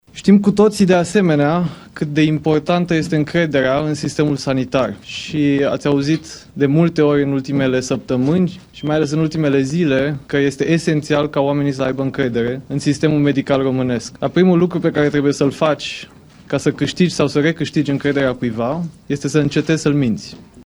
Vlad Voiculescu a făcut primele declarații într-o conferință de presă organizată la Ministerul Sănătății.